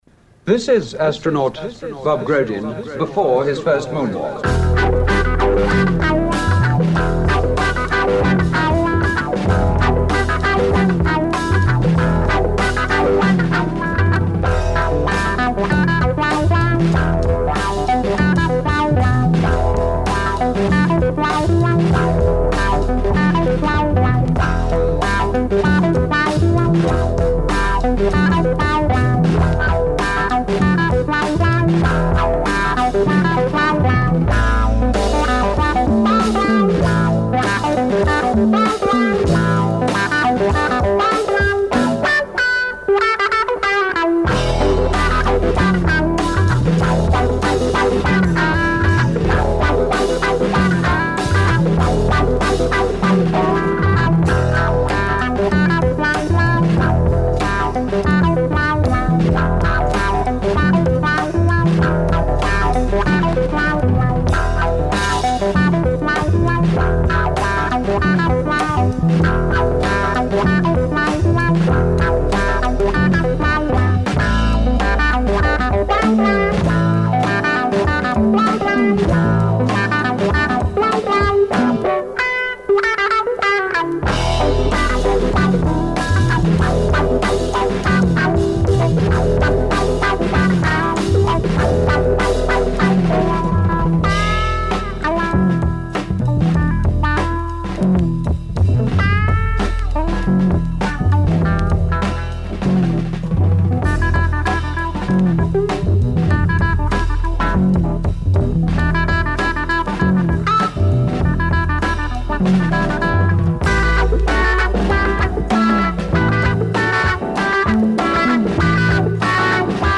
Avant-Garde Jazz Psych